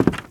High Quality Footsteps
Wood, Creaky
STEPS Wood, Creaky, Walk 03.wav